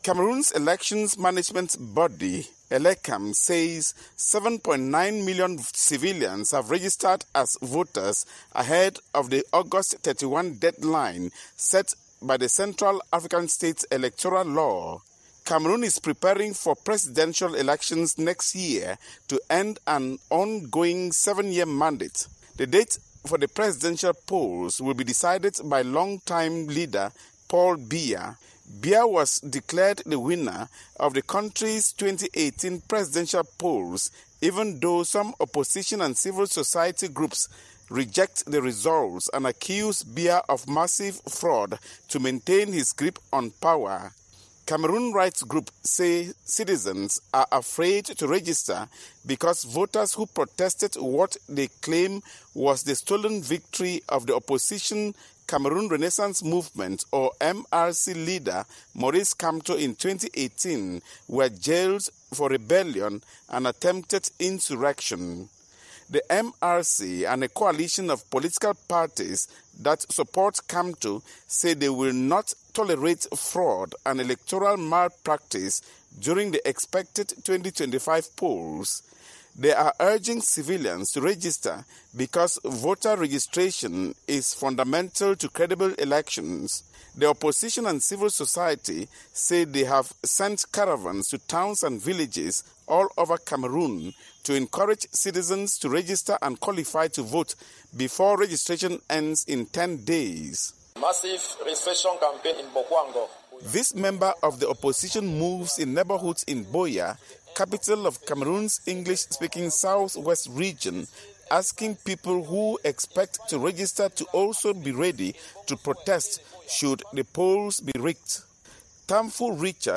reports from Yaounde that barely 50% of qualified civilians have registered for the polls expected in October 2025